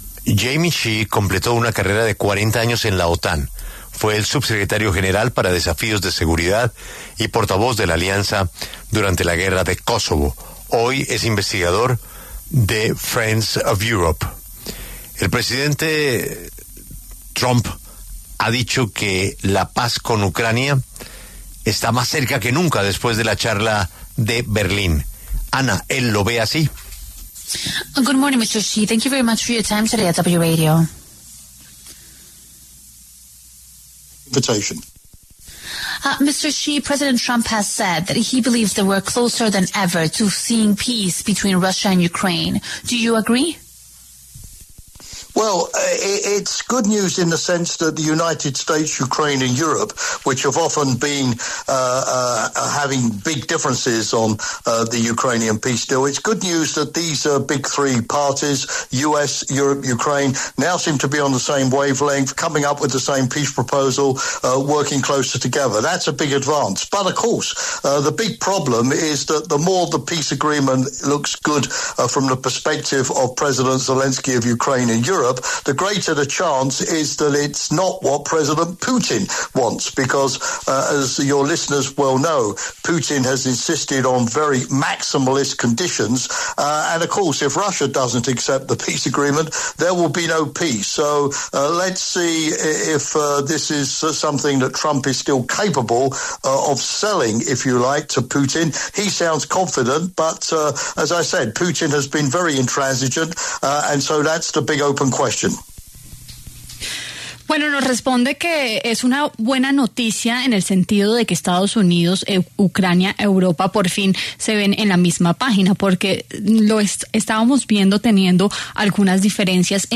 La W conversó con Jamie Shea, ex subsecretario general para Desafíos de Seguridad, quien aseguró que ahora el reto lo tiene Donald Trump, quien deberá convencer a Vladimir Putin de aceptar el acuerdo.